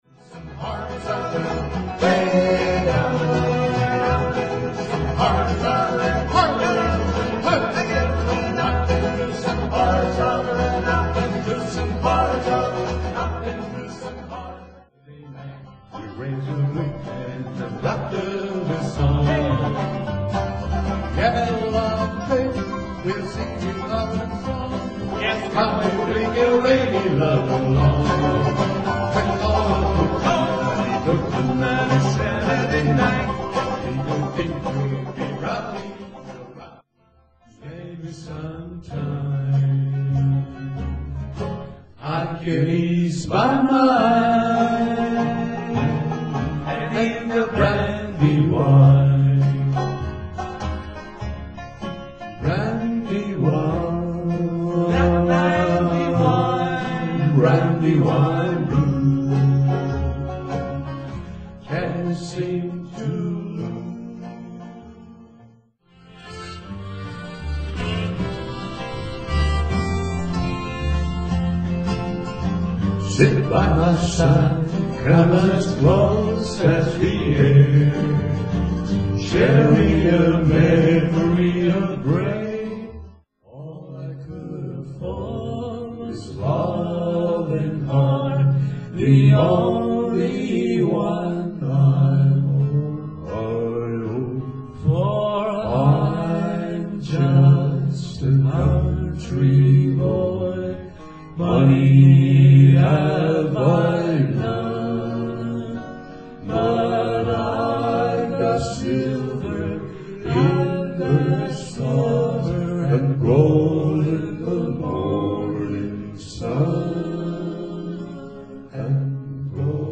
ひさびさの練習